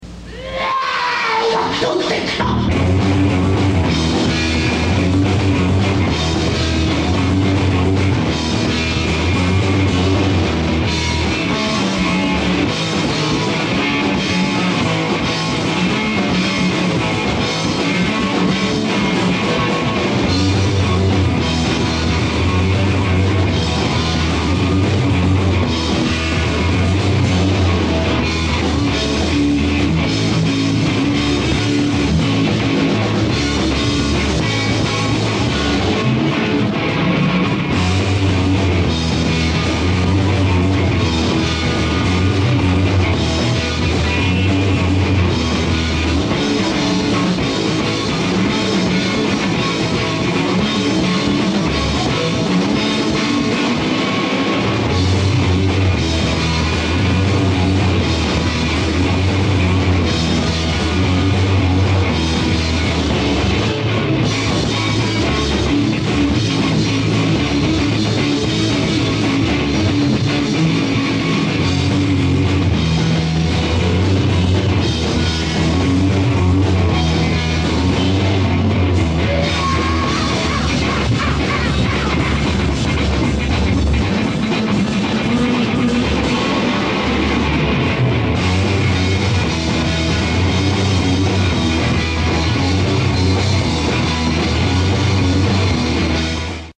single song